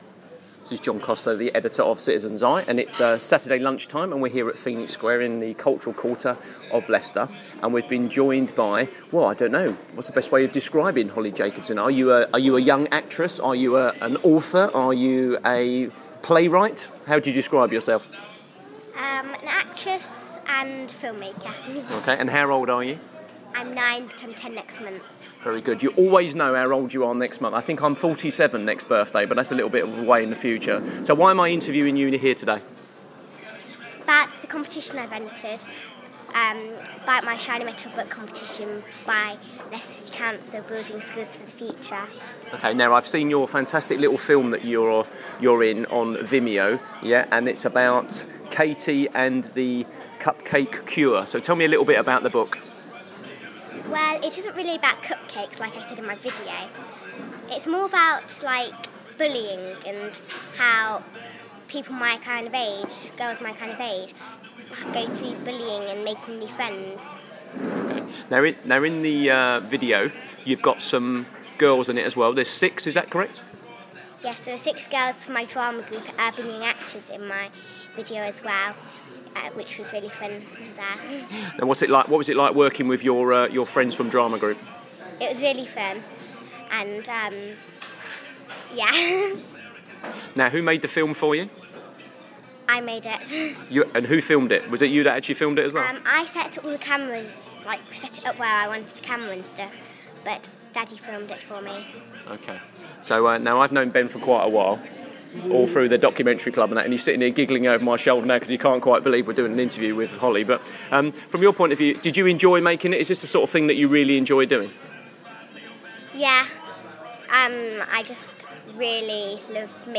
Interview
at Phoenix Square